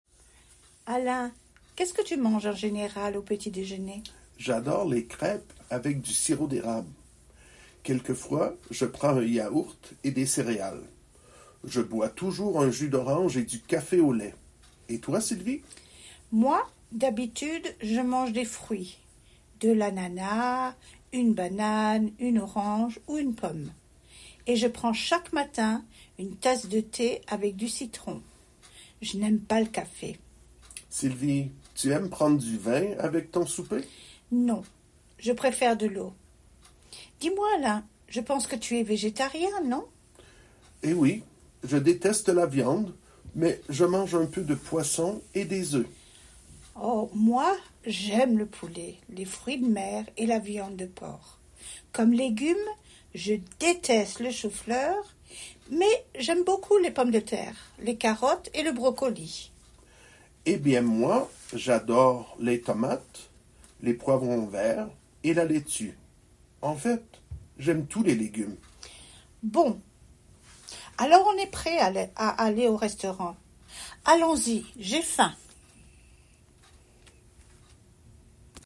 Dialogue – Un 7 | FrenchGrammarStudio